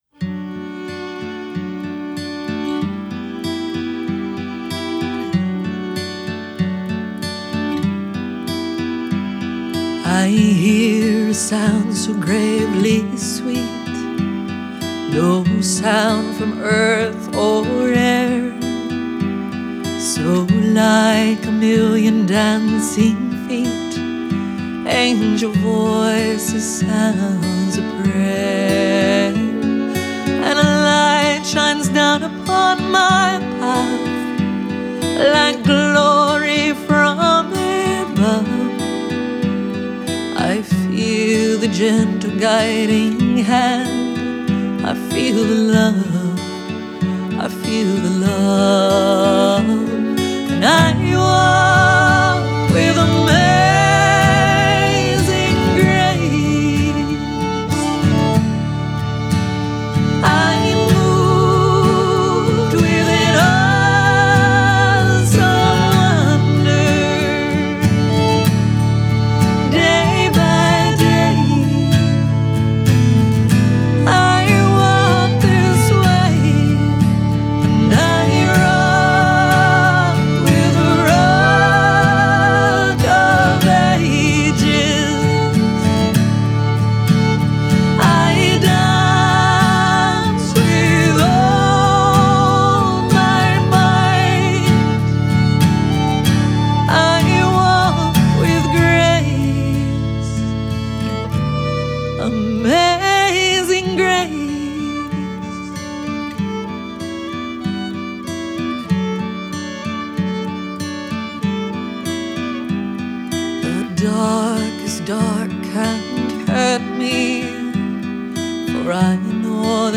Genre: Alternative Folk, Singer/Songwriter, Country